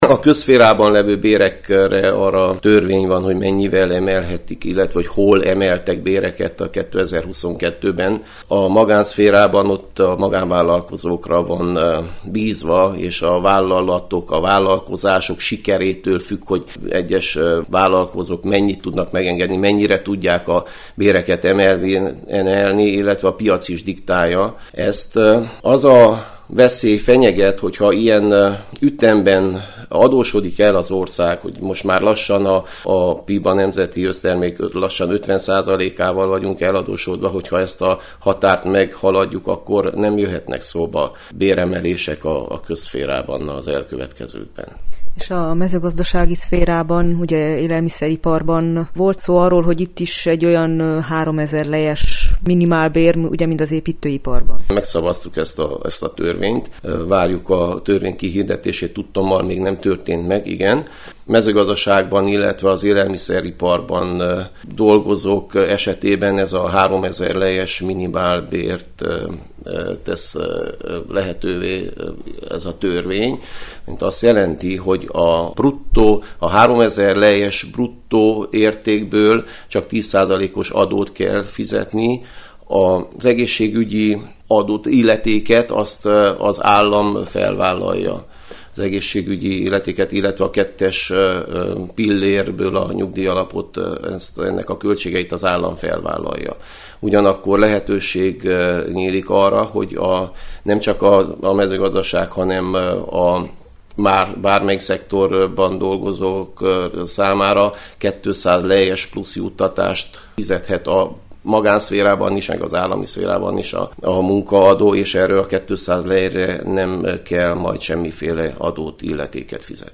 Erdélyben Maros megye a legelső, amely jégeső elhárító rendszerrel rendelkezik. Kolcsár Károly Maros megyei képviselőt kérdezte